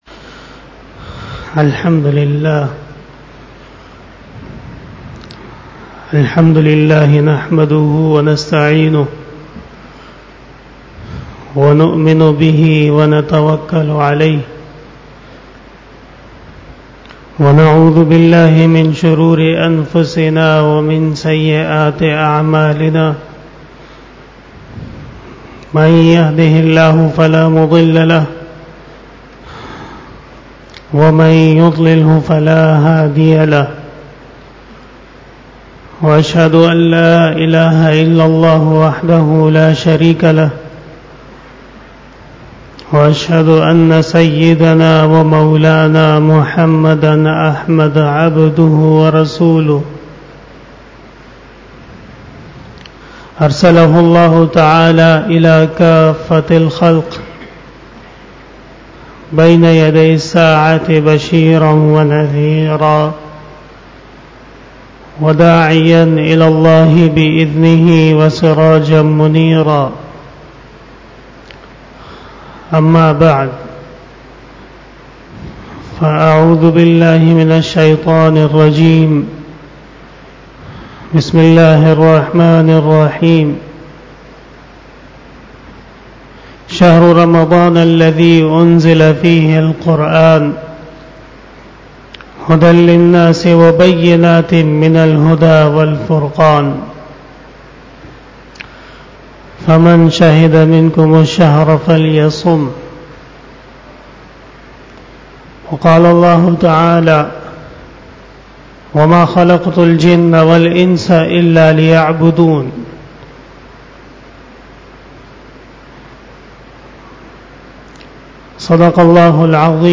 21 BAYAN E JUMA TUL MUBARAK (24 May 2019) (18 Ramadan 1440H)
Khitab-e-Jummah